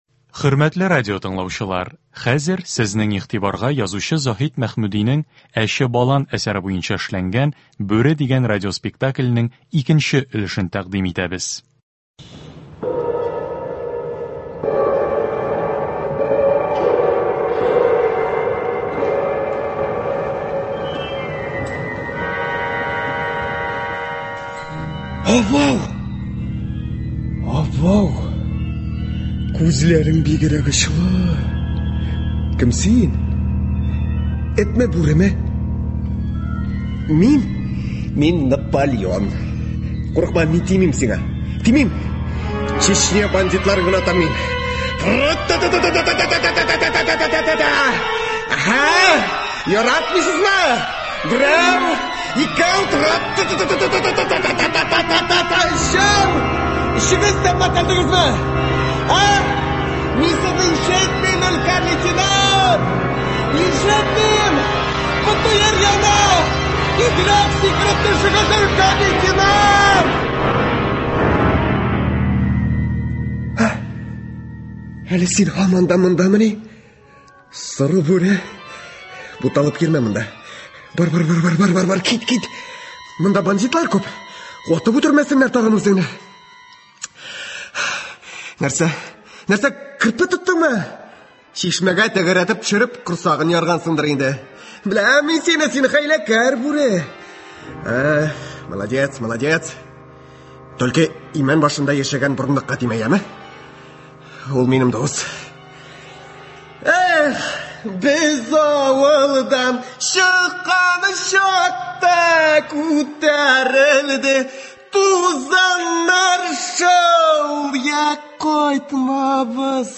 “Бүре”. Радиотамаша.